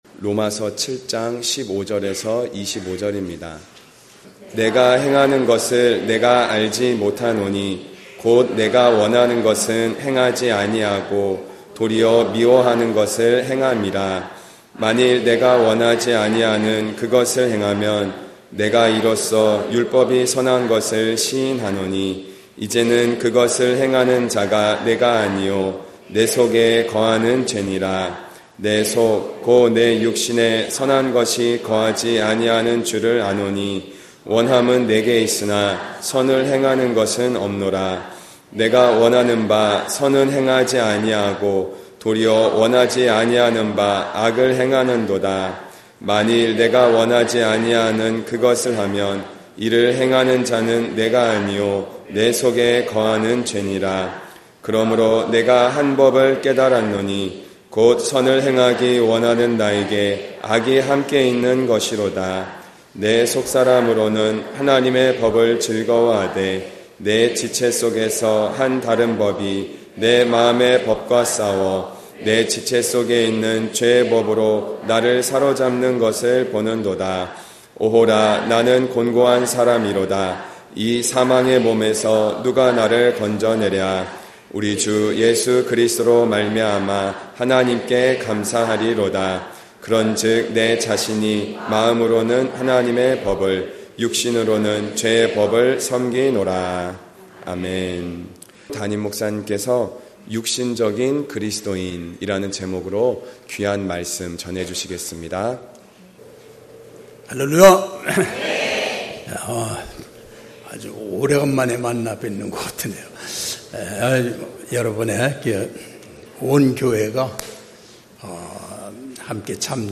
주일11시예배